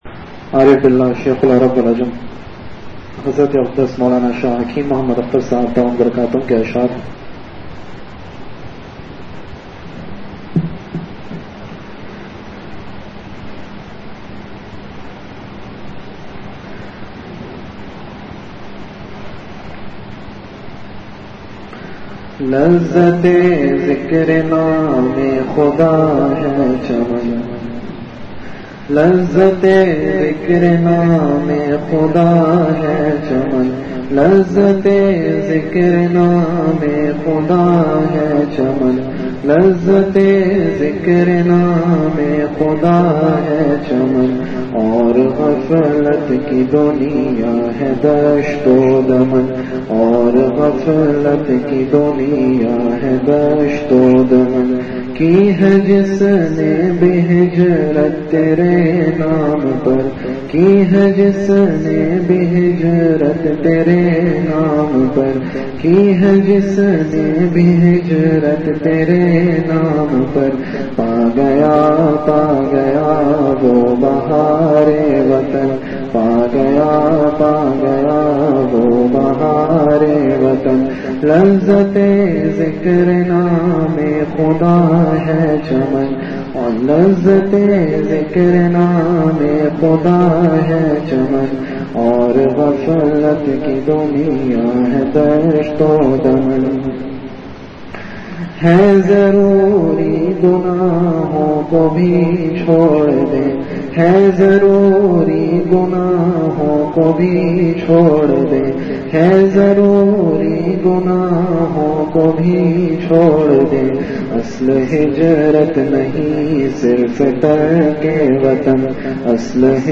Delivered at Home.